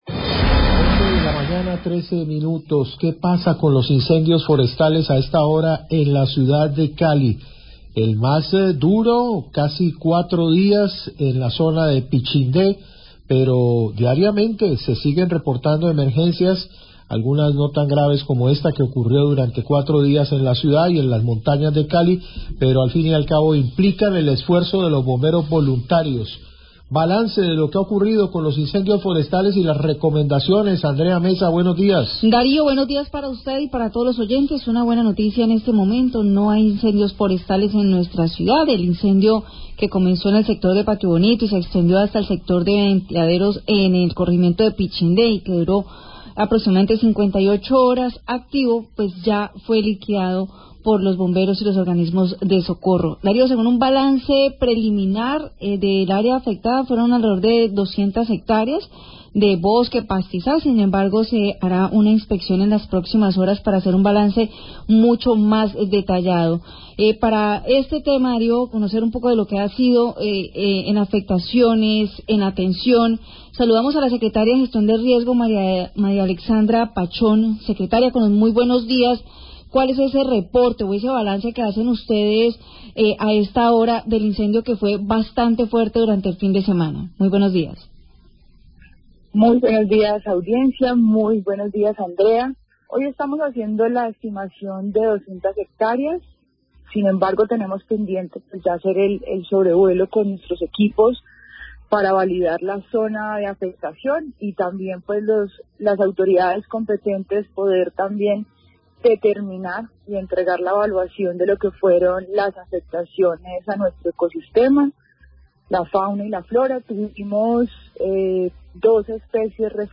Radio
La Secretaria de Gestión del Riesgo de Cali, María Fernanda Pachón, hace un balance de las afectaciones en fauna y flora por el incendio forestal del fin de semana en Ventiaderos (Pichindé) que afectó cerca de 200 hestareas de bosques.